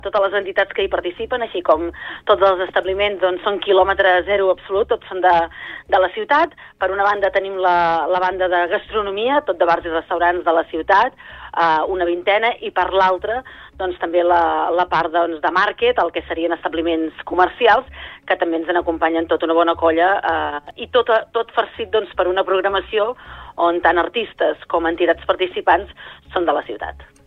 Al Supermatí hem parlat amb la regidora de Promoció econòmica de l’Ajuntament del municipi, Núria Cucharero, per parlar de la proposta i de les activitats que podrem trobar durant els tres dies de fira.